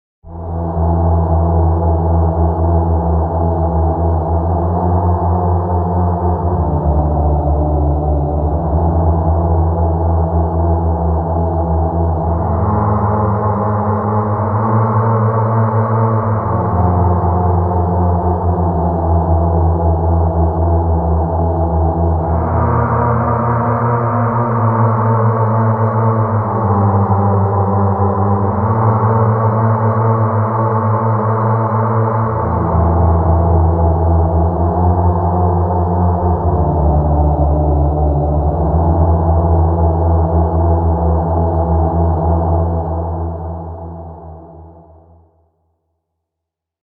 Scary Dark Atmospheric Sound Effect Free Download
Scary Dark Atmospheric